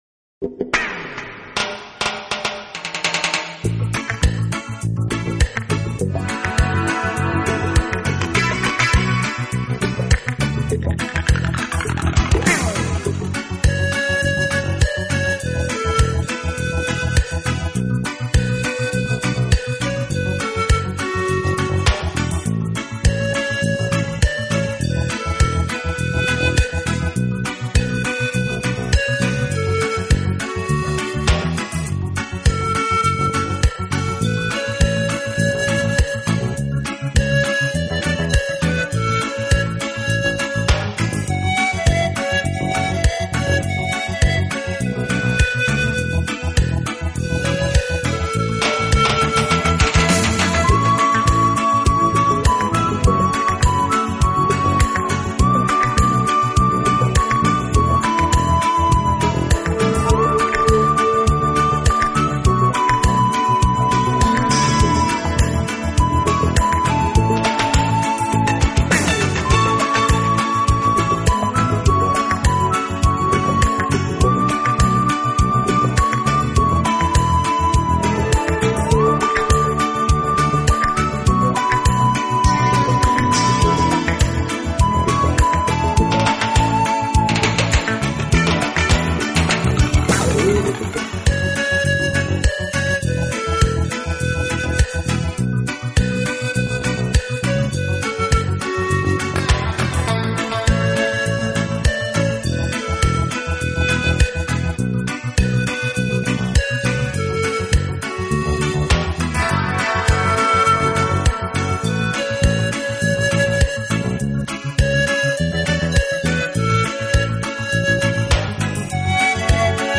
【乐器演奏】